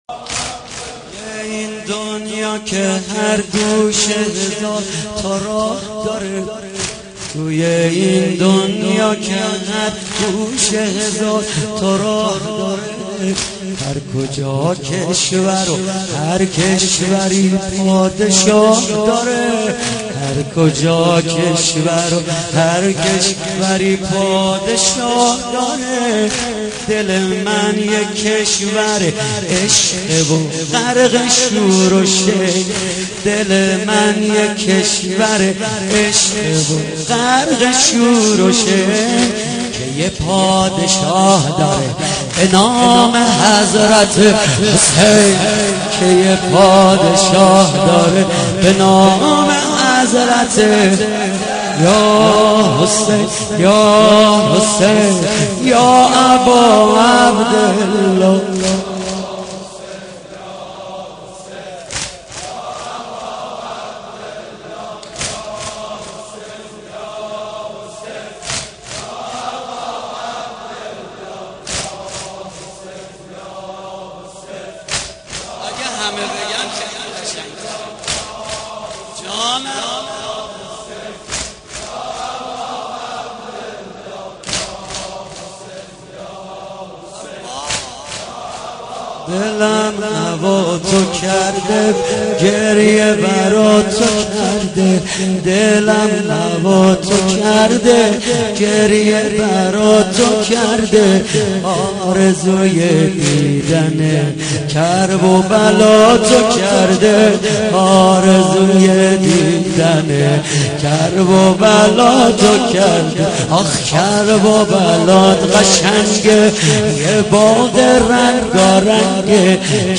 محرم 88 - سینه زنی 9
محرم-88---سینه-زنی-9